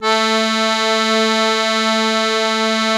MUSETTE 1 .2.wav